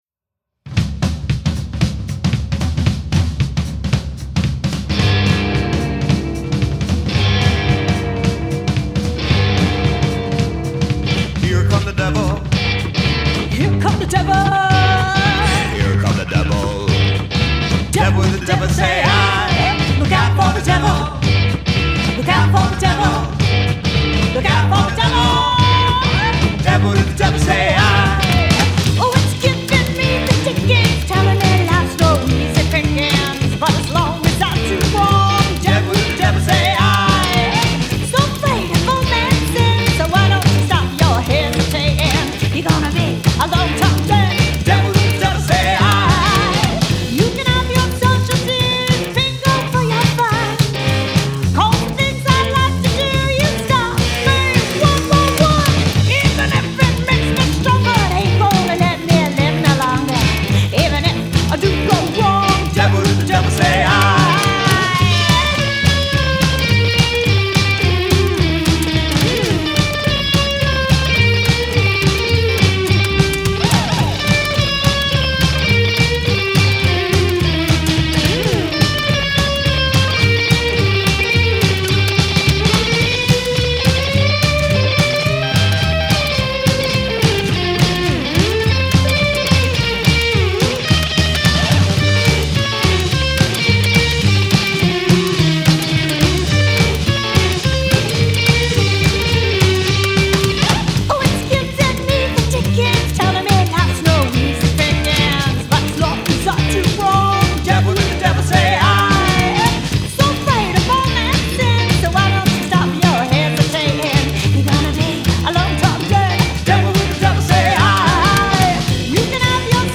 Swinging Boogie